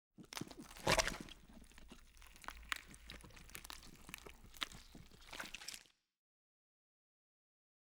burer_eat_0.ogg